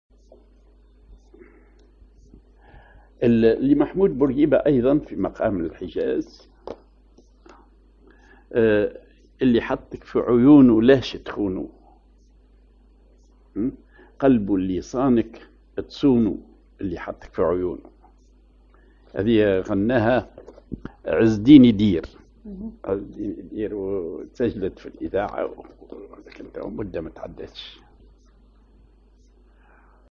Maqam ar الحجاز
Rhythm ar الوحدة
genre أغنية